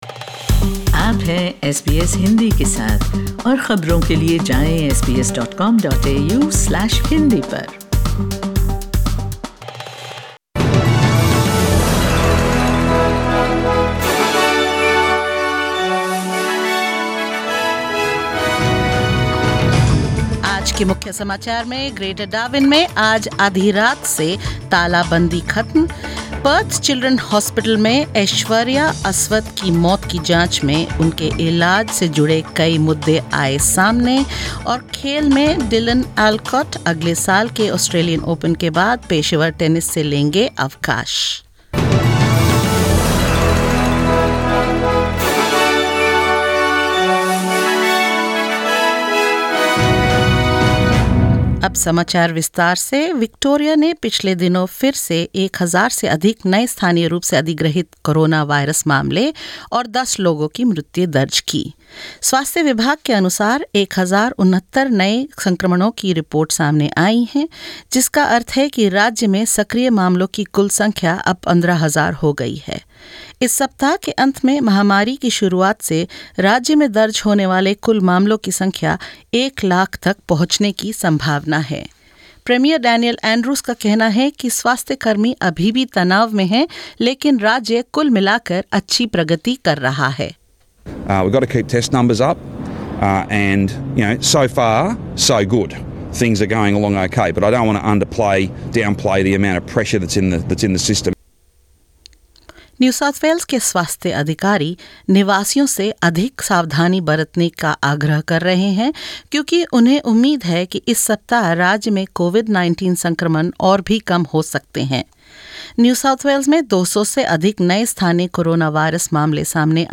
In this latest SBS Hindi news bulletin of Australia and India: Greater Darwin lockout to end despite the Northern Territory recording one new coronavirus case; Victoria records 1,069 new COVID-19 cases, taking the total tally closer to 100,000 cases since the start of the pandemic; Australian tennis champion Dylan Alcott to retire after next year’s Australian Open.